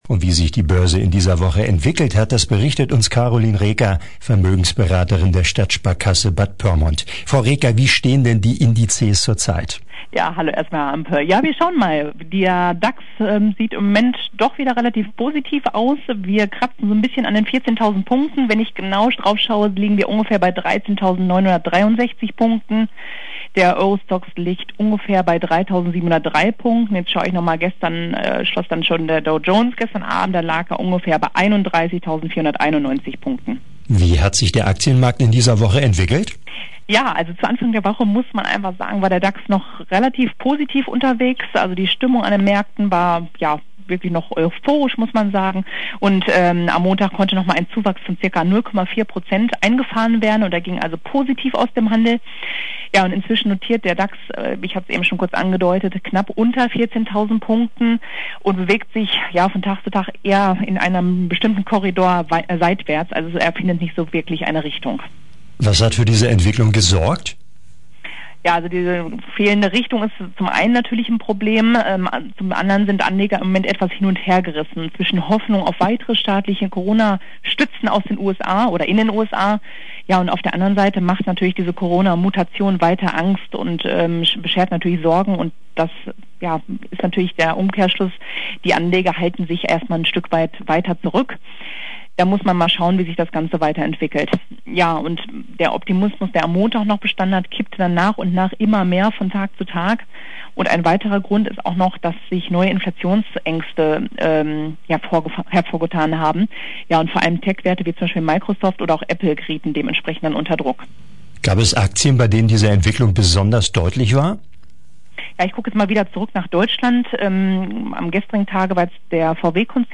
Börsengespräch